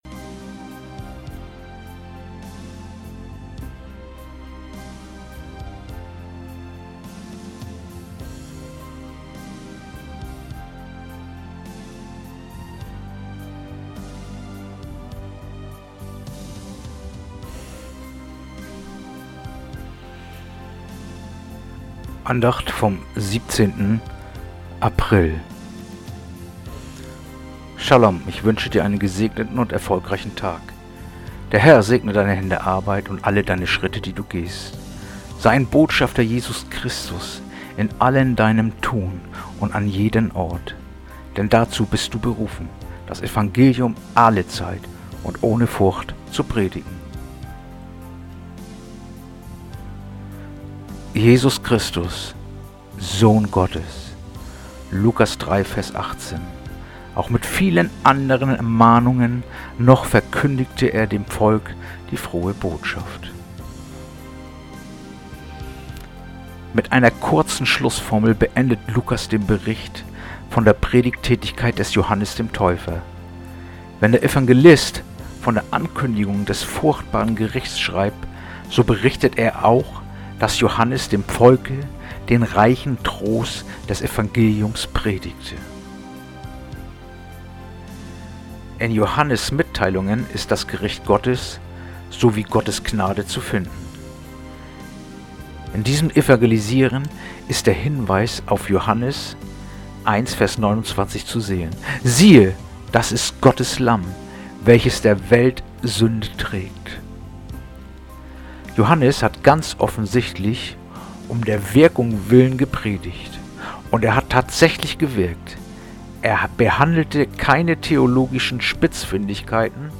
heutige akustische Andacht
Andacht-vom-17.-April-Lukas3-18.mp3